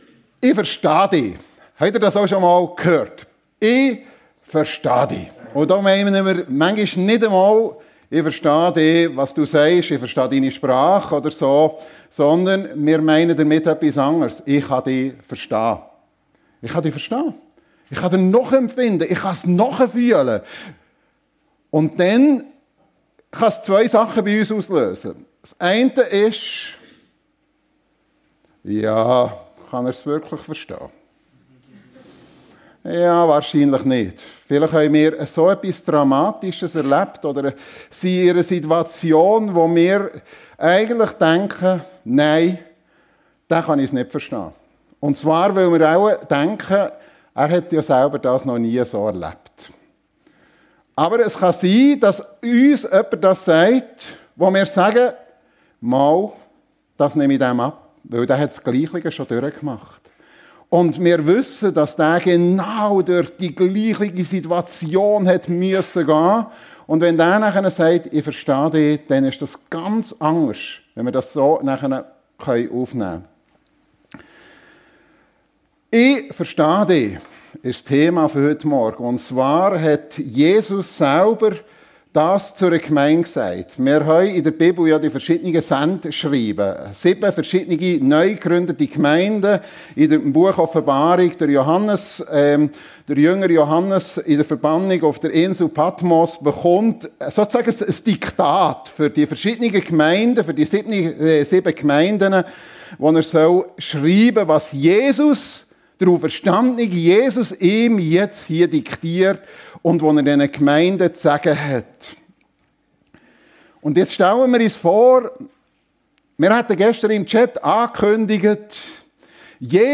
Jesus versteht dich! ~ FEG Sumiswald - Predigten Podcast